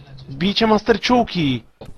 Worms speechbanks
FIRSTBLOOD.wav